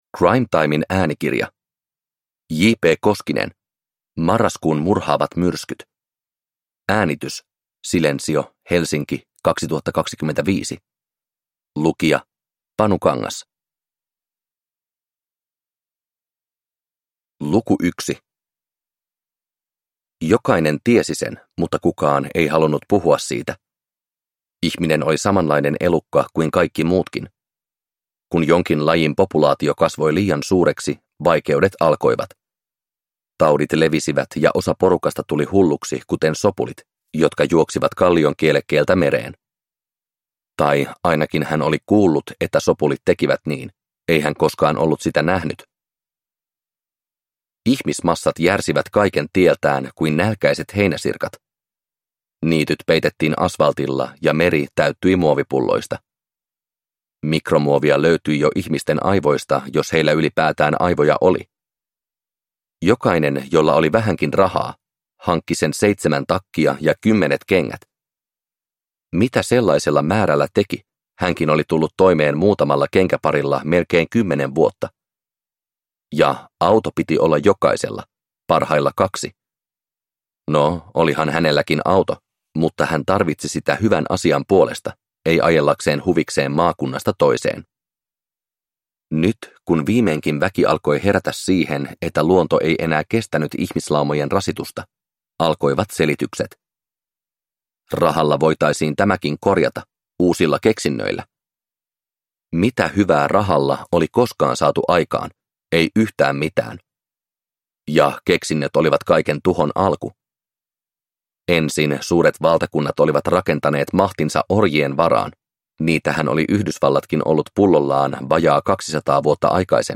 Marraskuun murhaavat myrskyt – Ljudbok